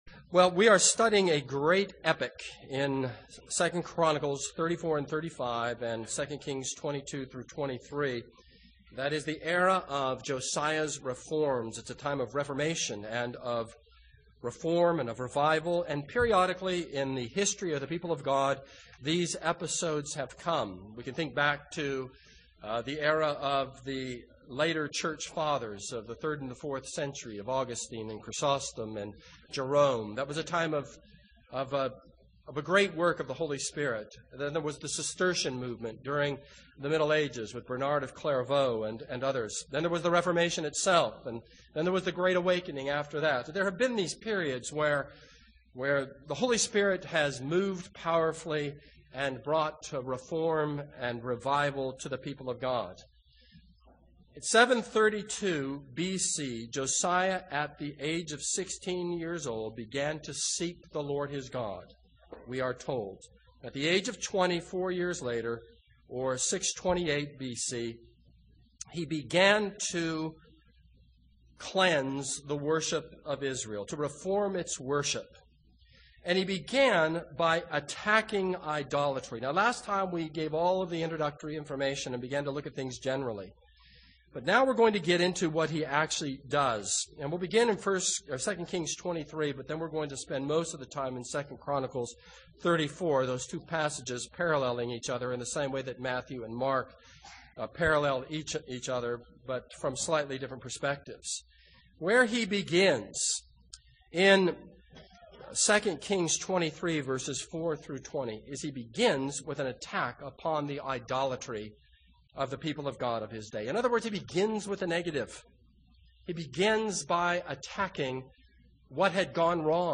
This is a sermon on 2 Kings 22-23:30.